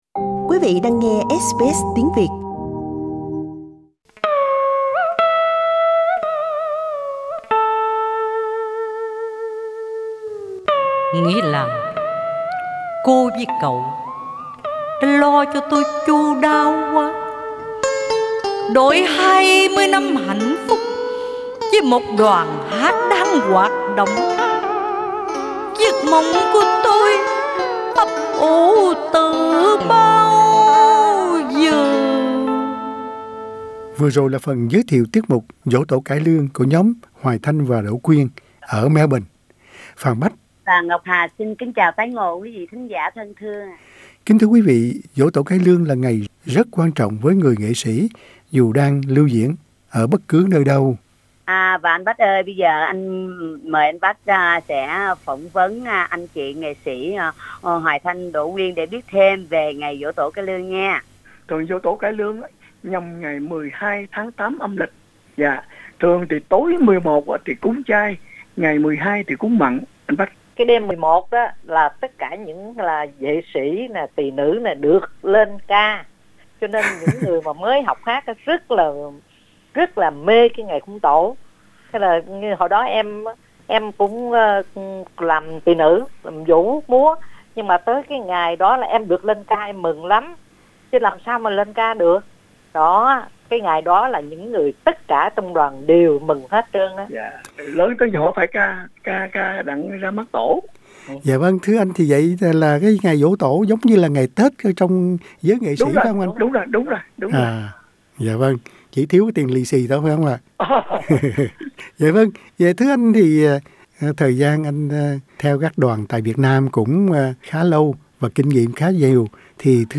Cải Lương